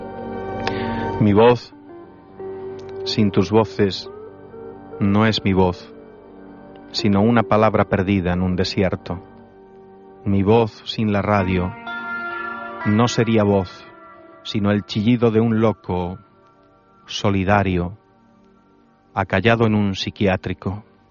Fragment extret del programa "Documentos" 70 años de RNE (2007)